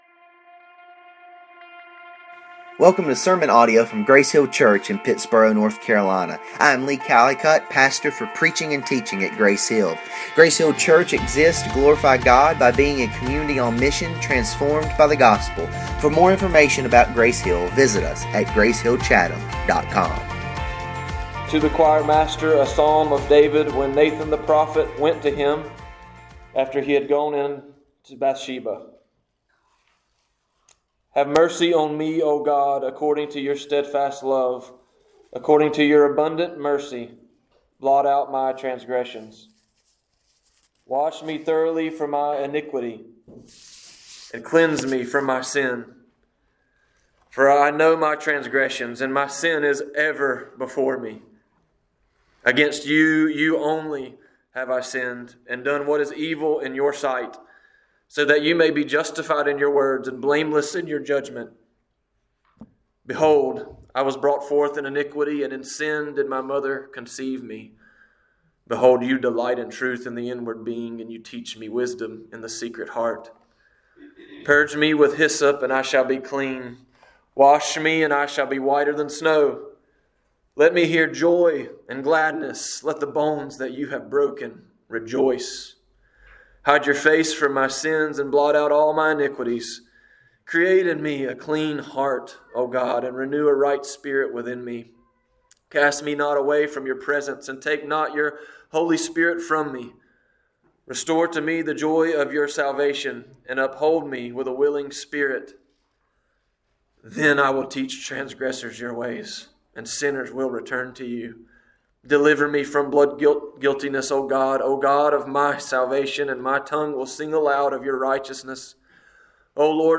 Standalone Sermons Passage: Psalm 51:1-19 « Lessons from Lot